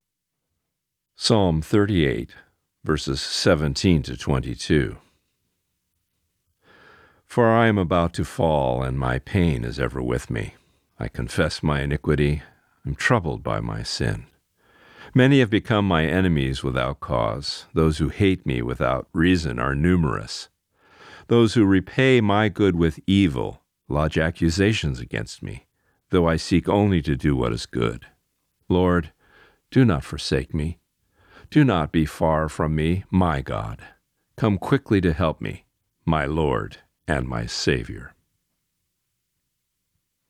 Reading: Psalm 38:17-22